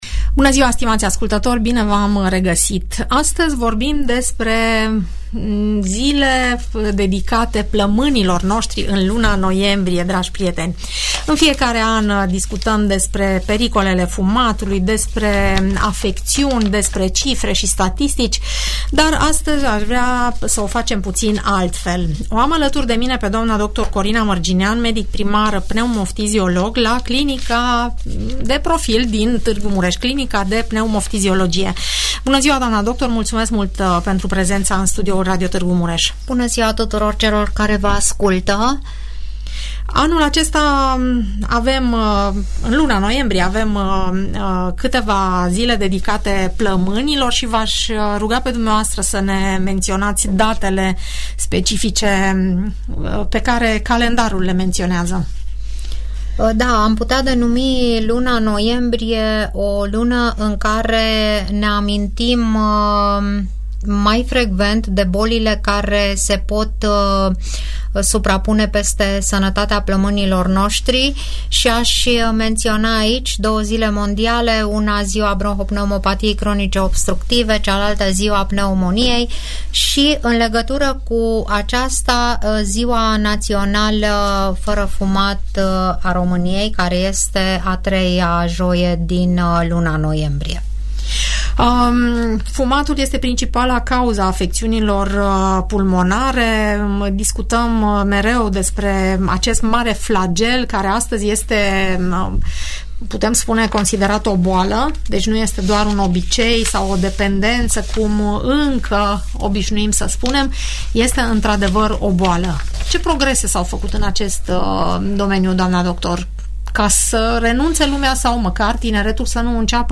» Implicațiile medicale, emoționale și sociale ale fumatului Implicațiile medicale, emoționale și sociale ale fumatului Renunțarea la fumat nu este deloc ușoară — de aceea, rolul părinților, profesorilor și al specialiștilor din sănătate este crucial în a-i ajuta pe copii să nu pornească pe acest drum și să evite fumatul sau utilizarea dispozitivelor electronice. Urmărește discuția moderată la Radio Tg Mureș
părerile ascultătorilor emisiunii, pe această temă.